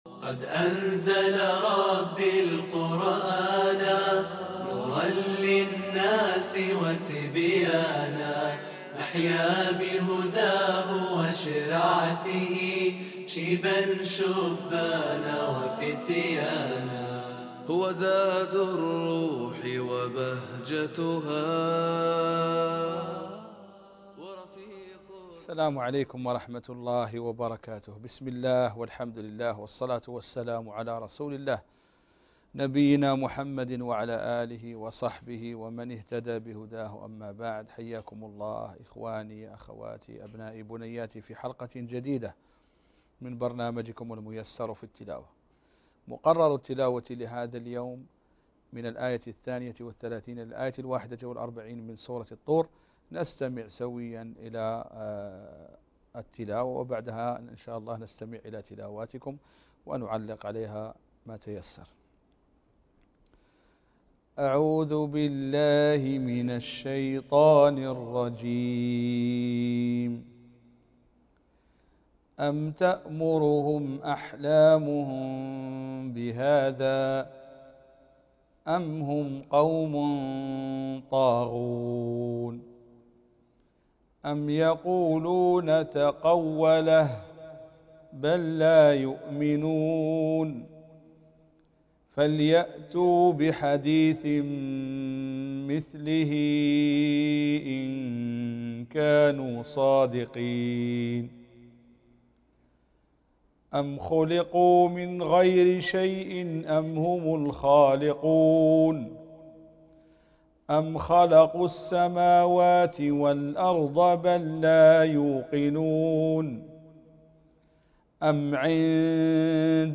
الميسر في التلاوة __ الشيخ المقرئ